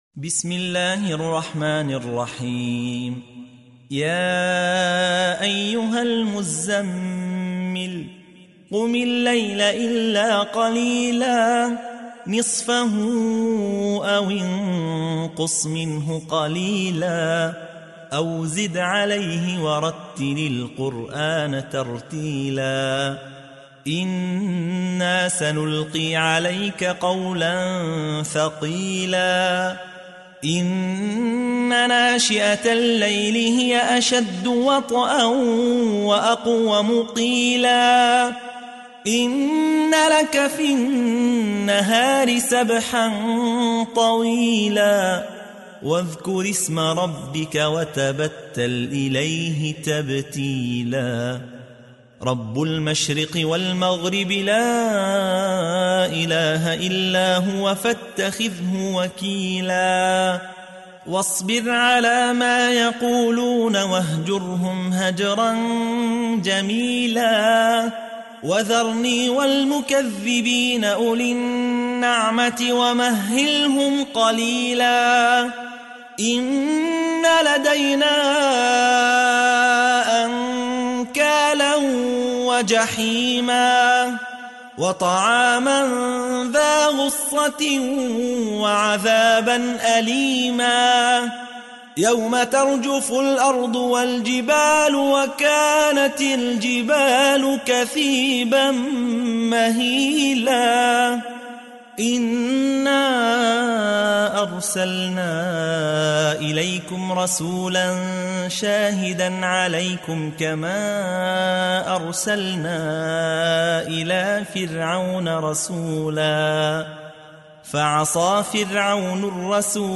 تحميل : 73. سورة المزمل / القارئ يحيى حوا / القرآن الكريم / موقع يا حسين
موقع يا حسين : القرآن الكريم 73.